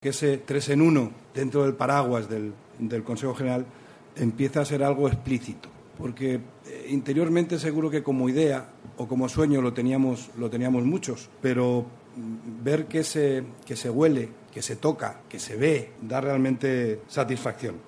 Los pasados 30 de junio y 1 de julio se celebró en Madrid reunión del Comité de Coordinación General (CCG) de nuestra Organización.